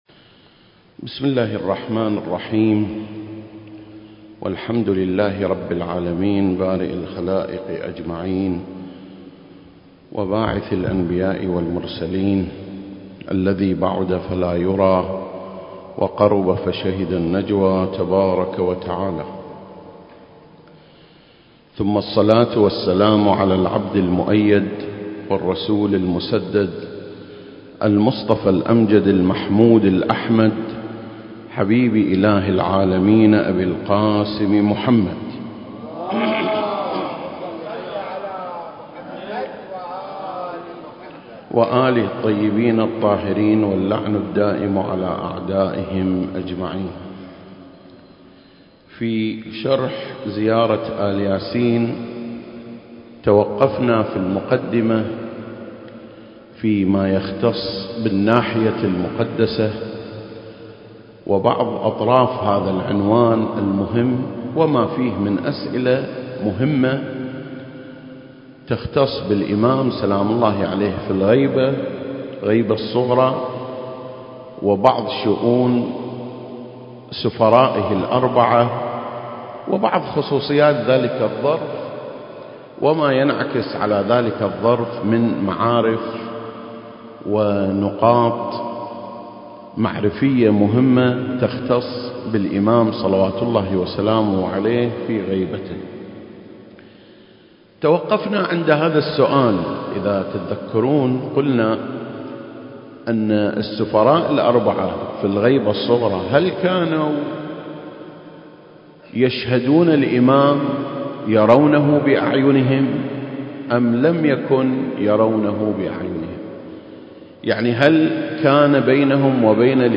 سلسلة: شرح زيارة آل ياسين (6) - الناحية المقدسة 3 المكان: مسجد مقامس - الكويت التاريخ: 2021